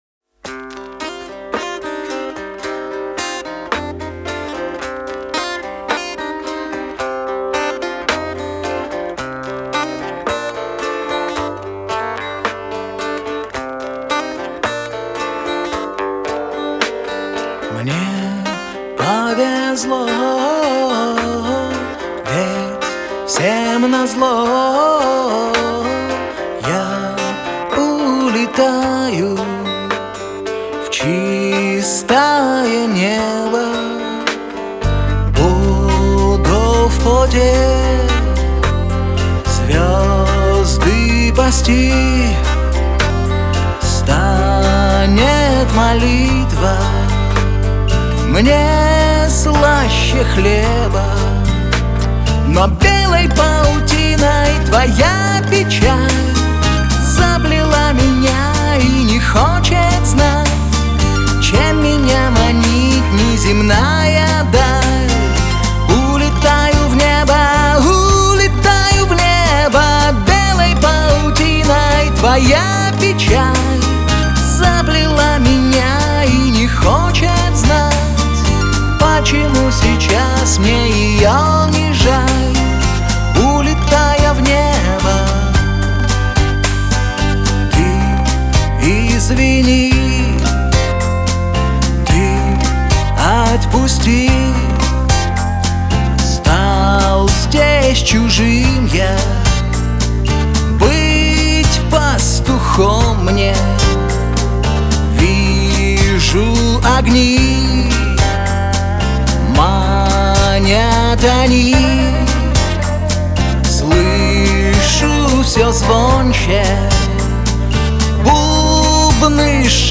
Русский Рок
Жанр: Рок